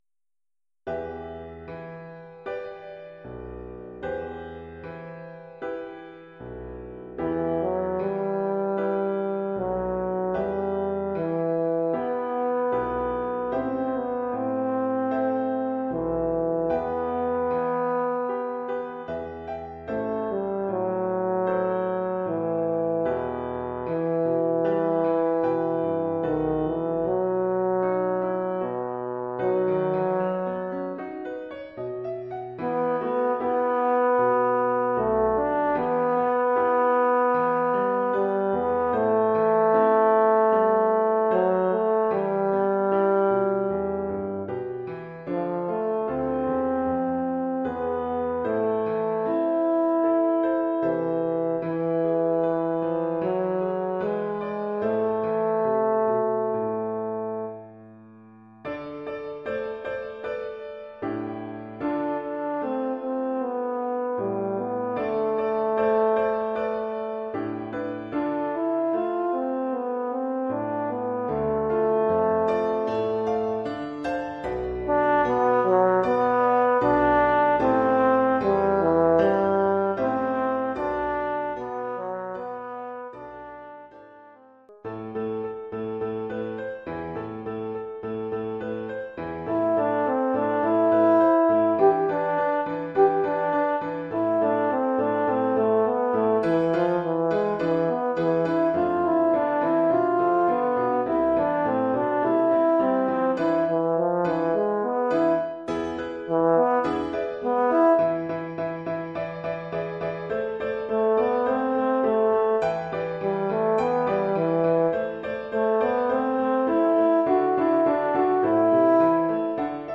Formule instrumentale : Cor et piano
Oeuvre pour cor d’harmonie et piano.